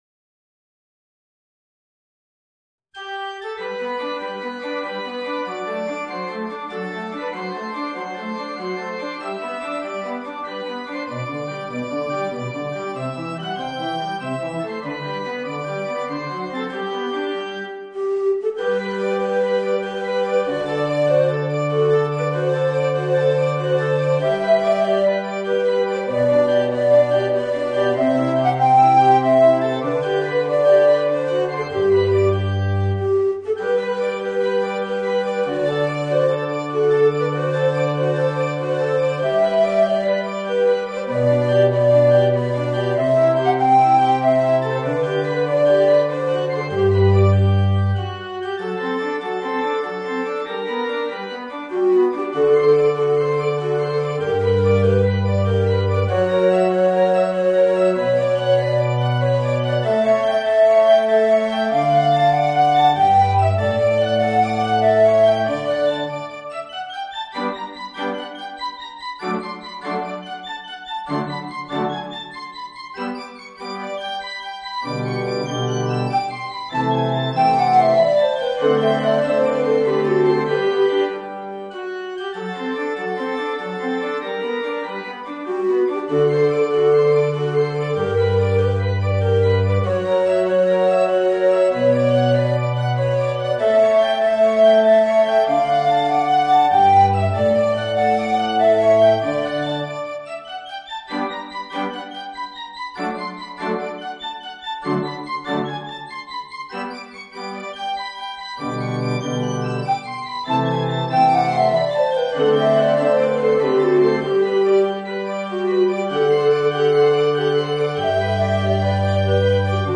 Voicing: Tenor Recorder and Piano